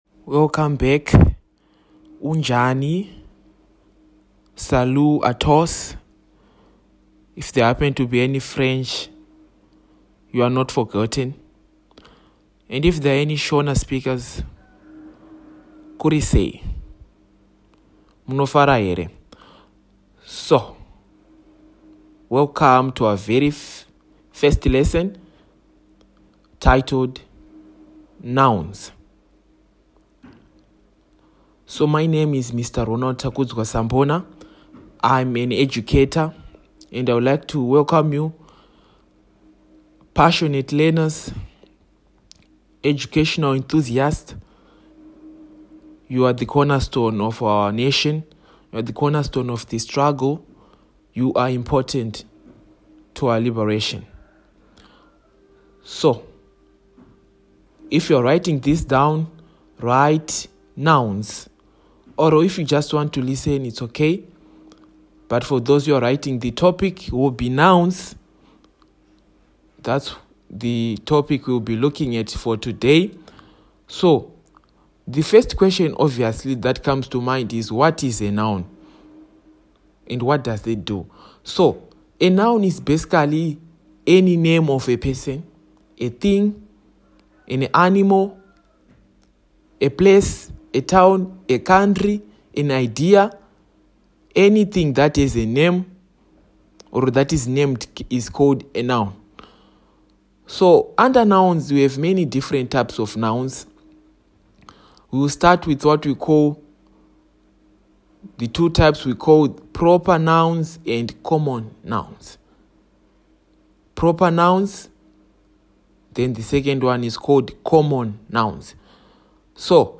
An introductory audio lesson exploring the main types of nouns with clear examples and the key differences between them.
lesson-1-types-of-nouns.mp3